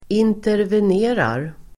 Uttal: [interven'e:rar]